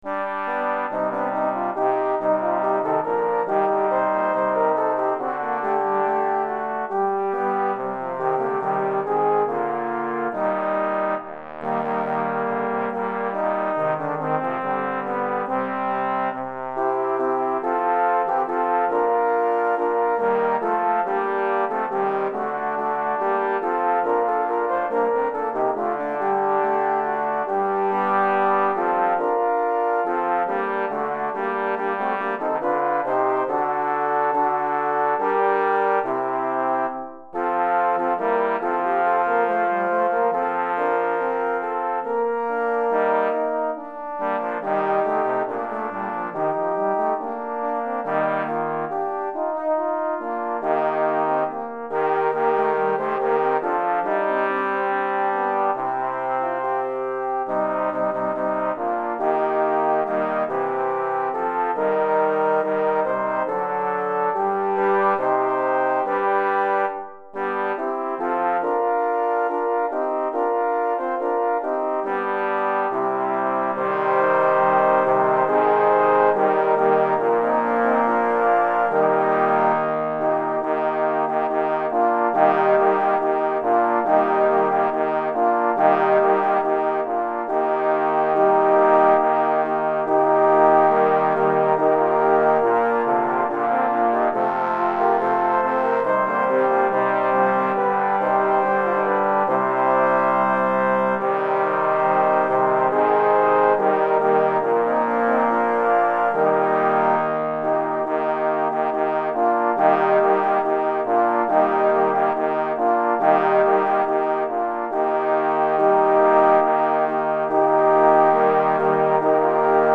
Répertoire pour Trombone - 6 Trombones 2 Trombone Basses